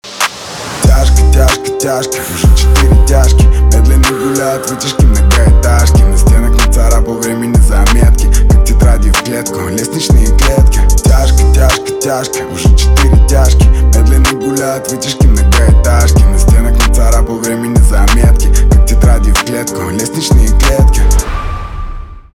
русский рэп , пианино
басы
грустные